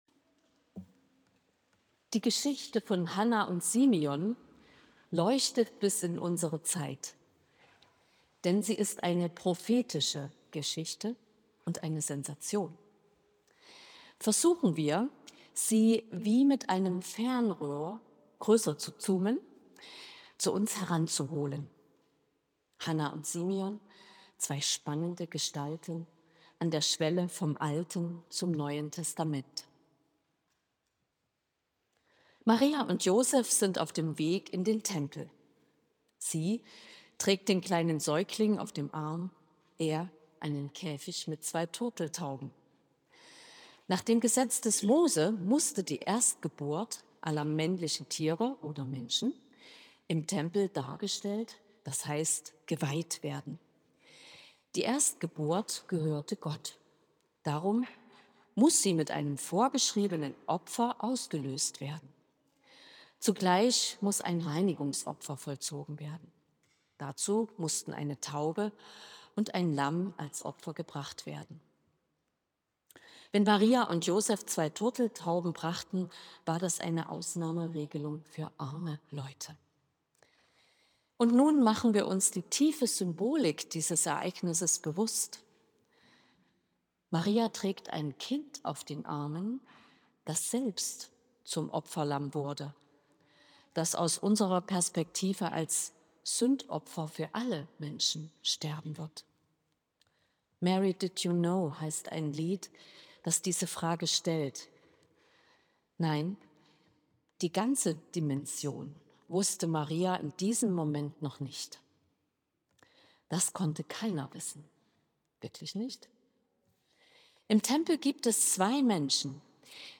Predigt
Klosterkirche Volkenroda, 28.